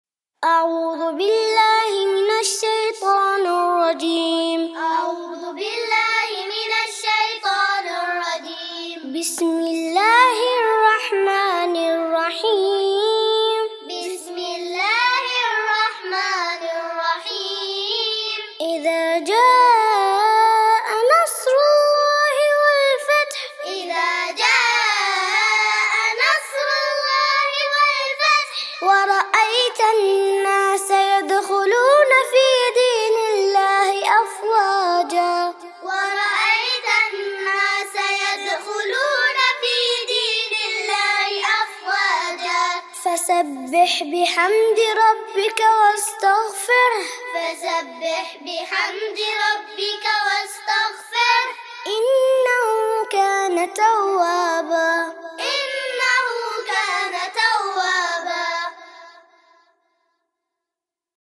النصر (تعليمي للصغار) - لحفظ الملف في مجلد خاص اضغط بالزر الأيمن هنا ثم اختر (حفظ الهدف باسم - Save Target As) واختر المكان المناسب